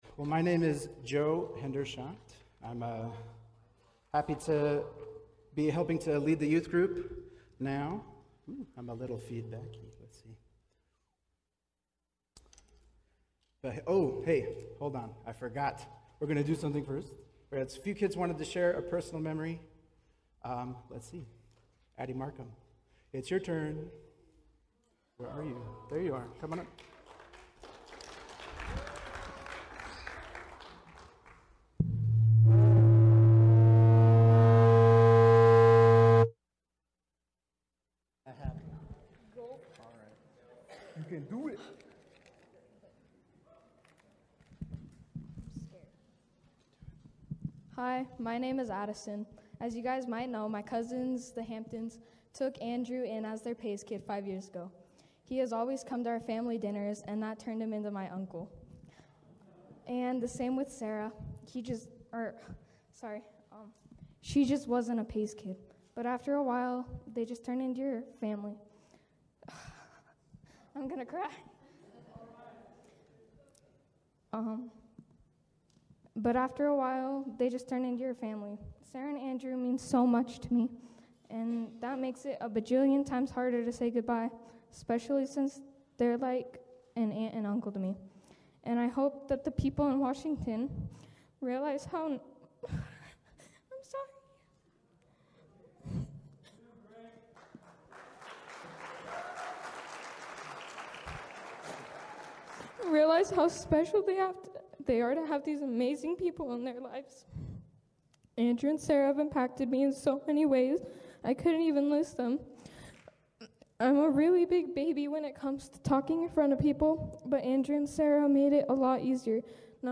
8.31.25-sermon-audio-only.mp3